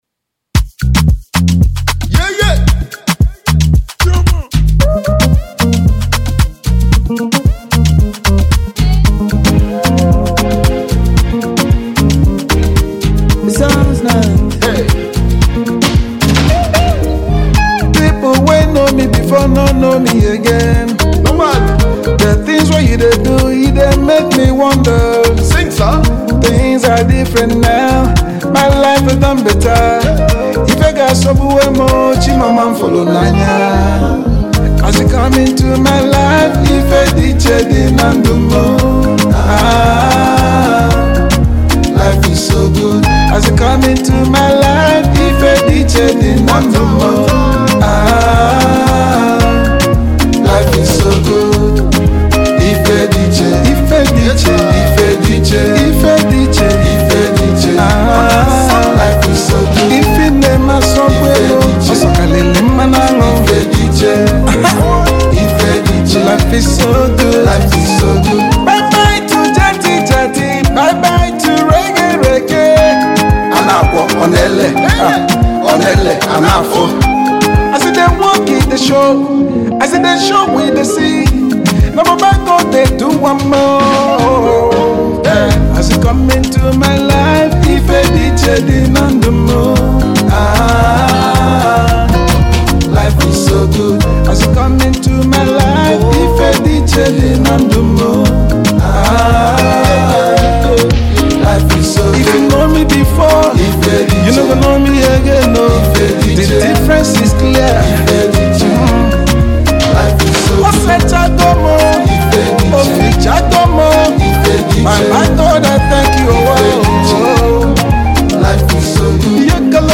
gospel
soulful vocals
against a backdrop of vibrant instrumentation.